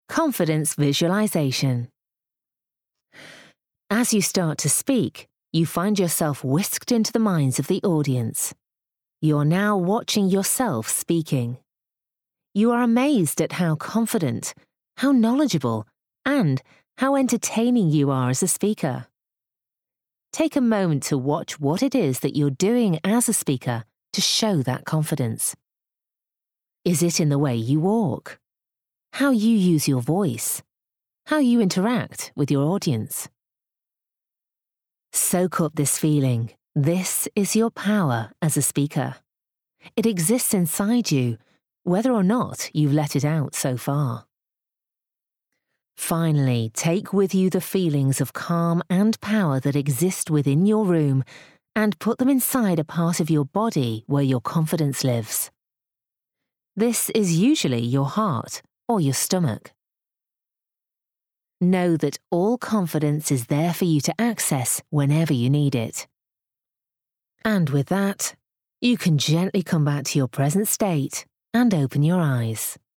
30/40's Northern/Spanish, Believable/Familiar/Soothing
• Audio Books